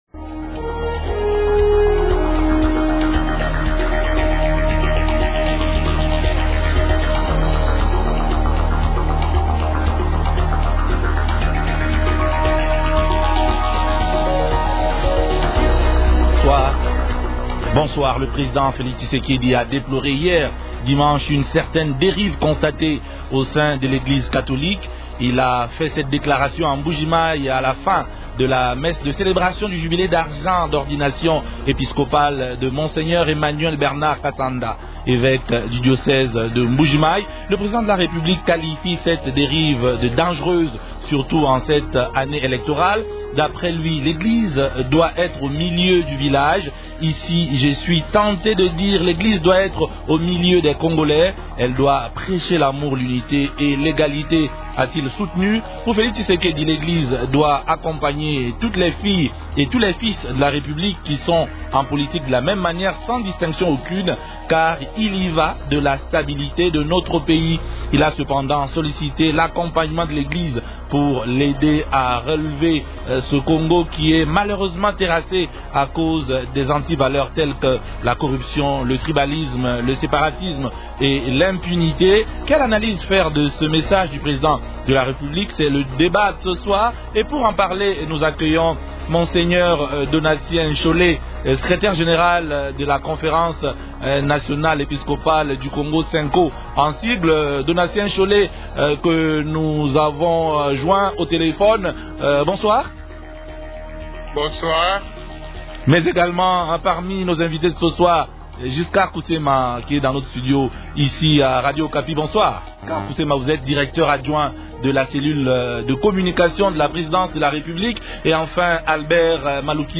-Quelle analyse faire du message du chef de l’Etat à l’endroit de l’Eglise catholique ? Invités :